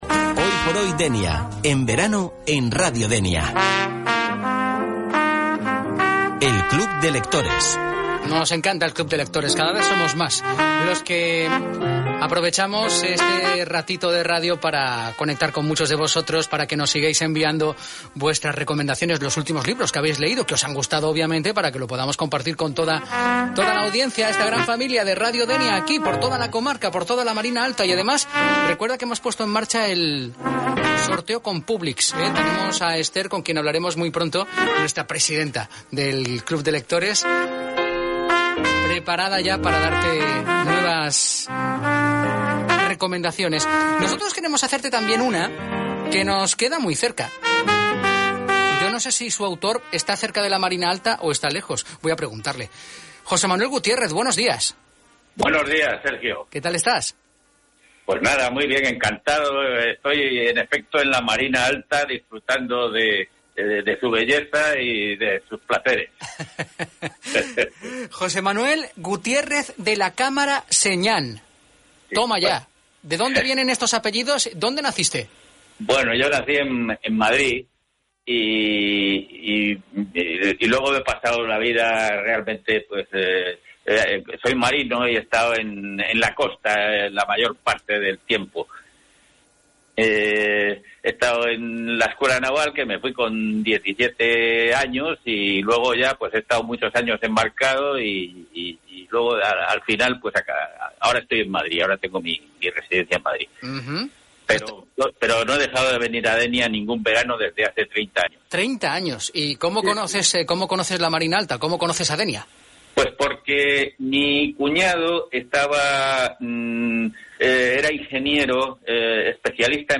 Radio DENIA realiza una entrevista